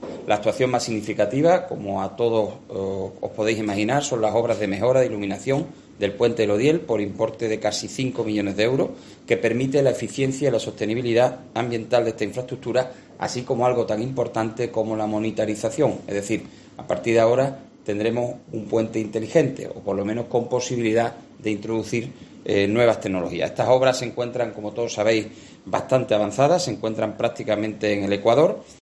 Jaime Pérez, delegado de Fomento en Huelva
Así, lo han señalado hoy el delegado del Gobierno andaluz en Huelva, José Manuel Correa, y el delegado territorial de Fomento, Articulación del Territorio y Vivienda, Jaime Pérez, en rueda de prensa.